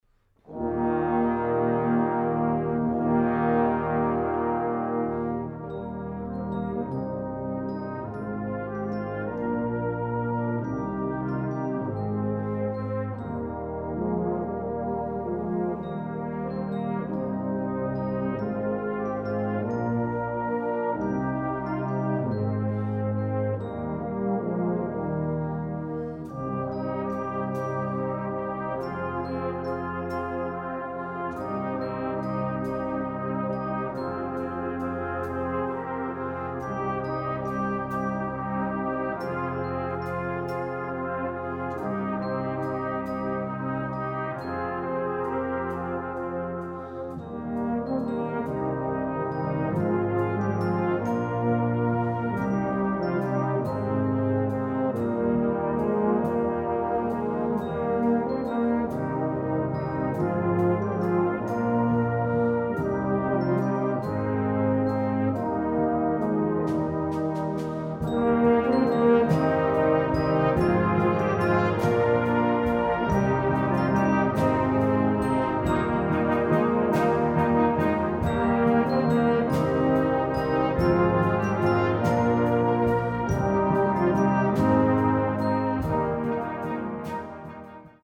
Scottish Traditional.
Blasorchester PDF